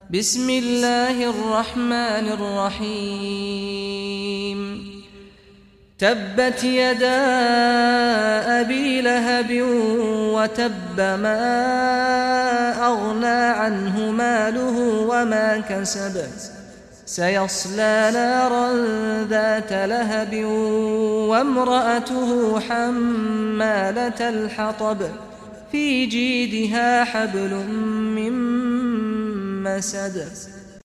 Surah Al-Masad Recitation by Sheikh Saad al Ghamdi
Surah Al-Masad, listen or play online mp3 tilawat / recitation in Arabic in the beautiful voice of Sheikh Saad al Ghamdi.